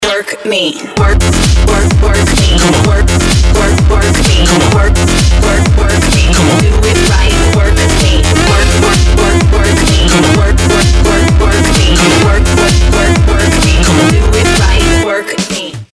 • Качество: 320, Stereo
Хип-хоп
electro
Крутой Електро Хип-хоп